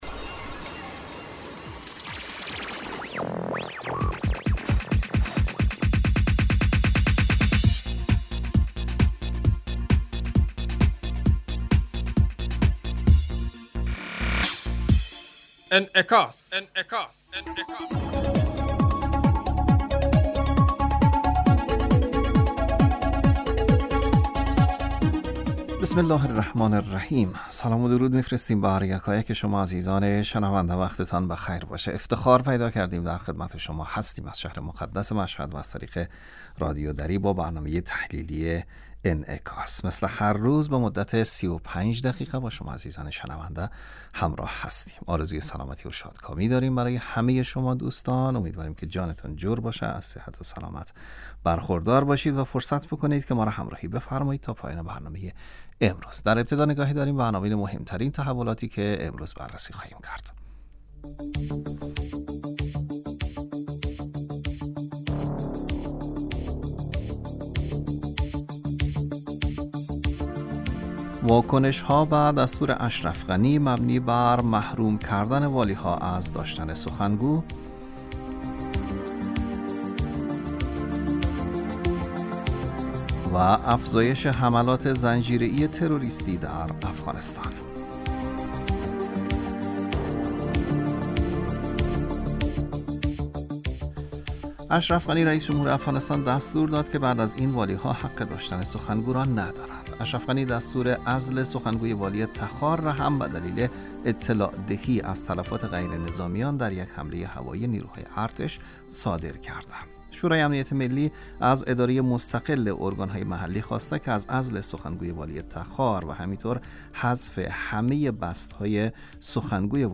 برنامه انعکاس به مدت 35 دقیقه هر روز در ساعت 12:00 ظهر (به وقت افغانستان) بصورت زنده پخش می شود.